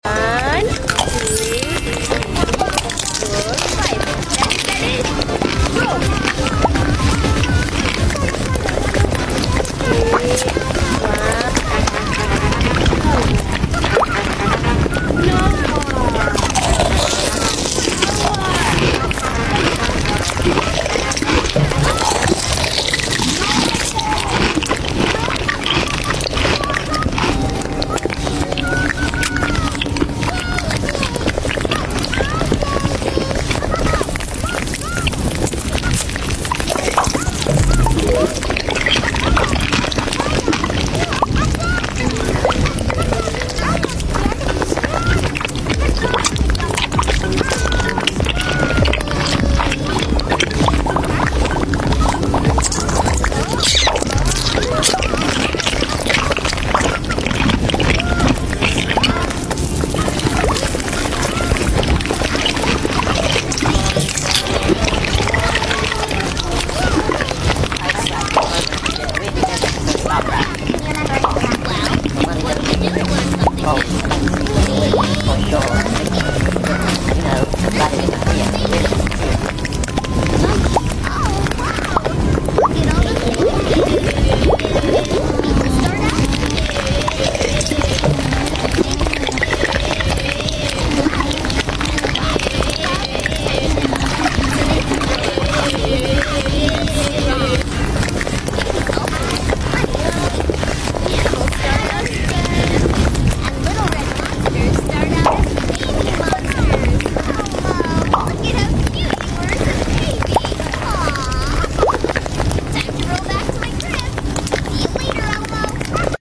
Roblox eating with blob emoji sound effects free download